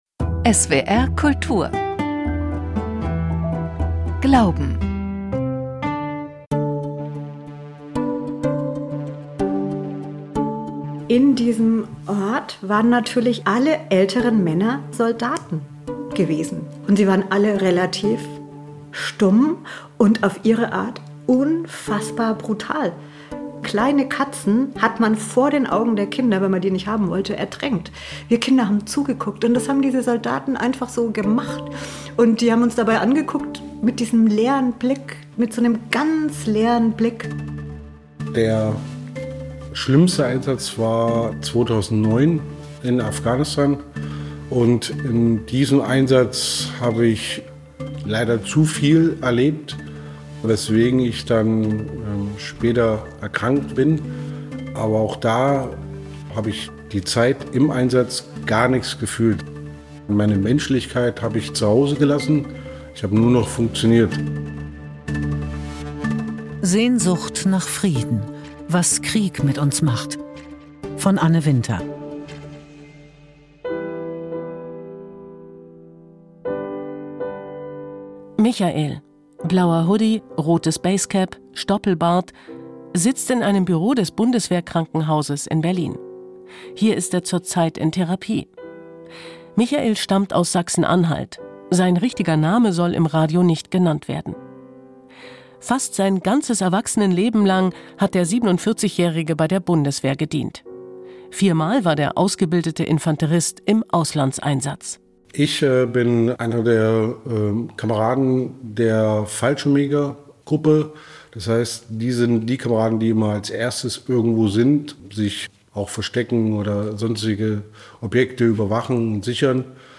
Gespräche mit Soldaten, Seelsorgern, Therapeuten, Kriegsenkeln über die Frage, was Krieg mit Menschen macht.